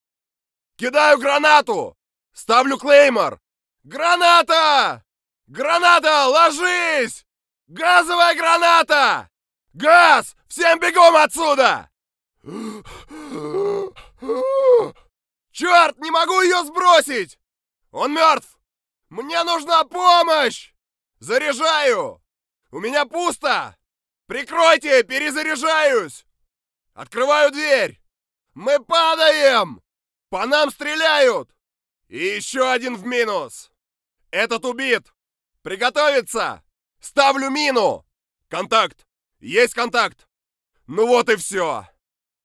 Language - Russian, Timbre - a baritone. Russian voice-over.
Sprechprobe: Industrie (Muttersprache):